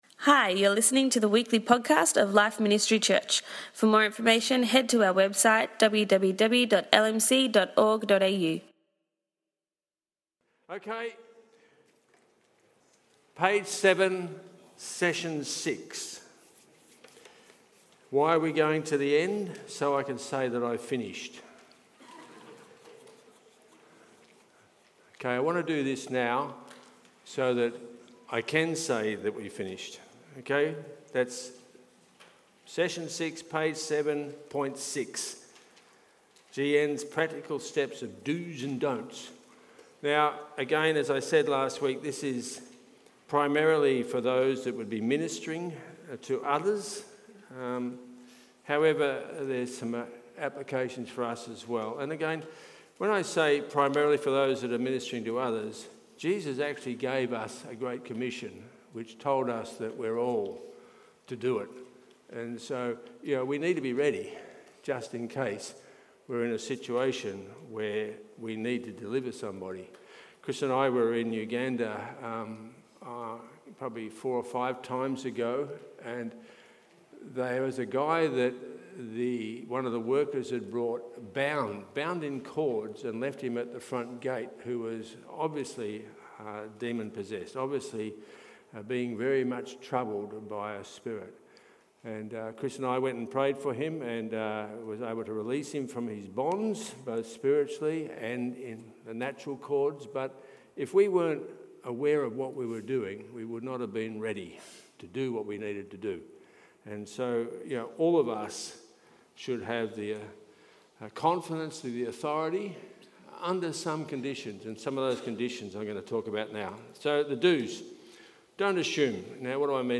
Tonight we completed our Deep Dive teaching series on The Spirit Realm with Part 7.